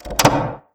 Antique Lock Normal Unlock - modified.wav